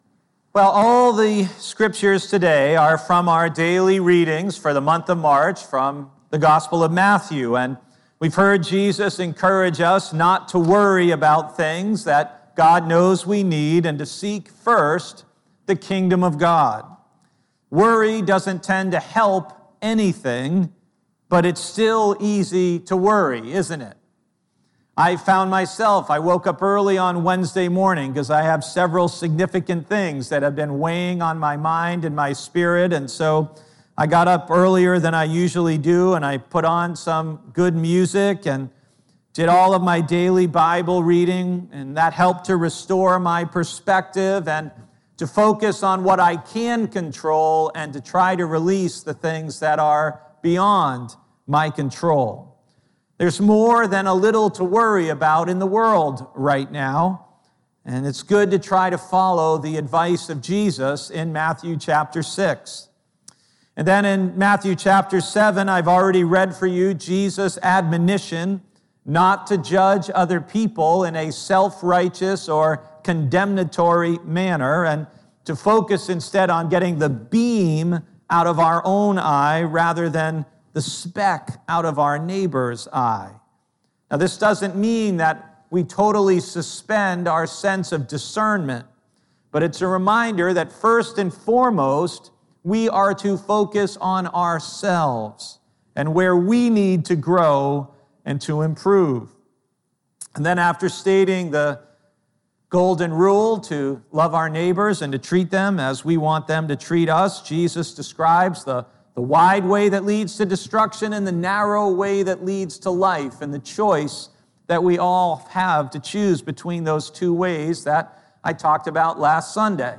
Words You Don't Want to Hear - Brewster Baptist Church